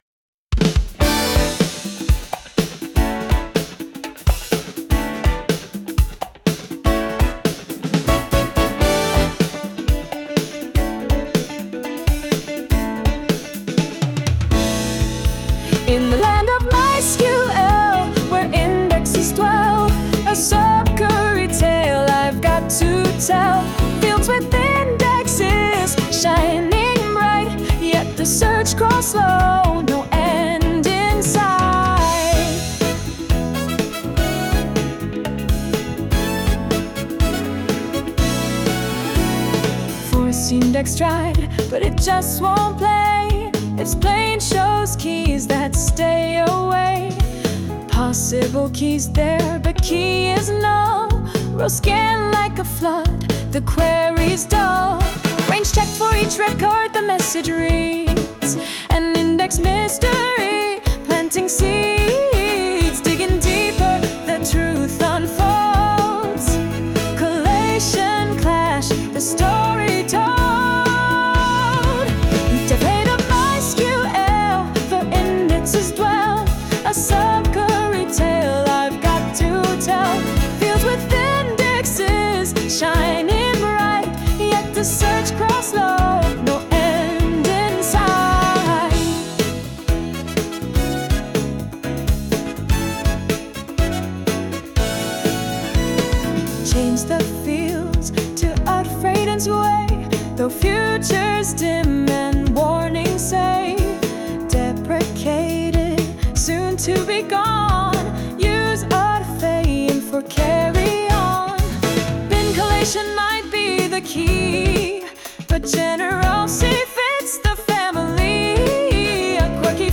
Sing this blog article